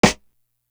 Grand Entry Snare.wav